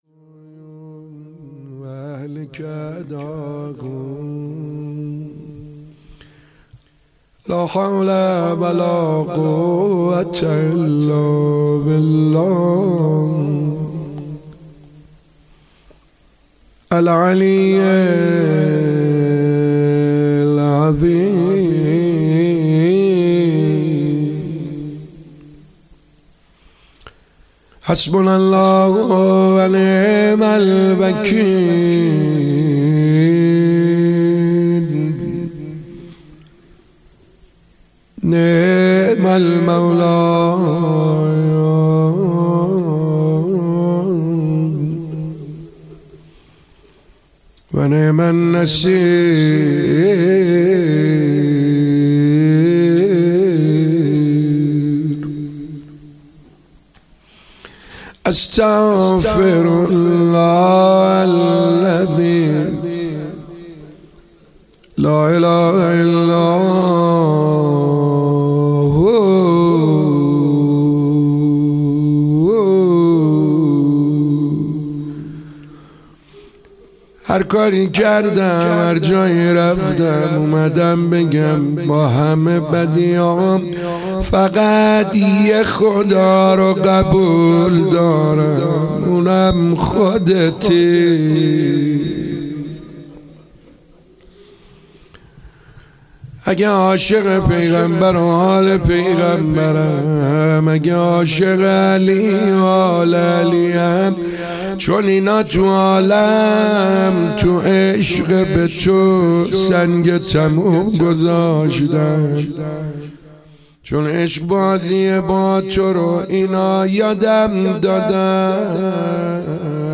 مناجات‌خوانی